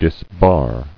[dis·bar]